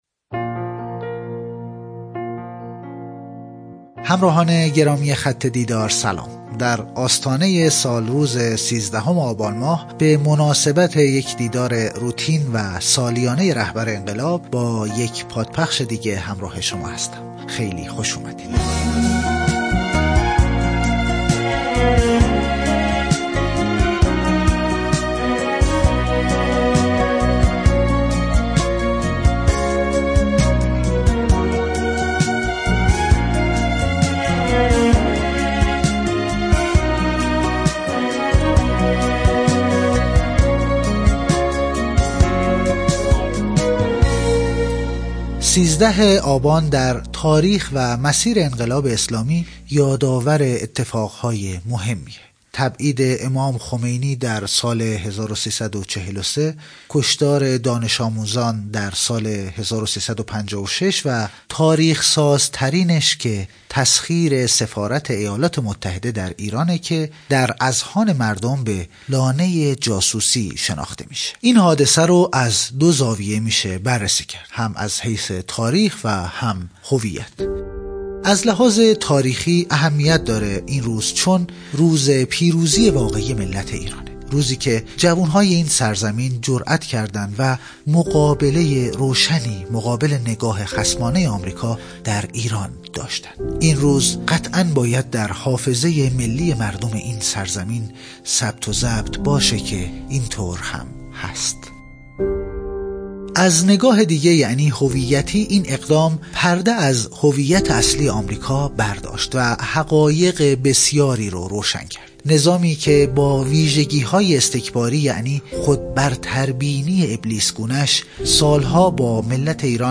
مرور صوتی بیانات رهبر انقلاب در دیدار دانش‌آموزان و دانشجویان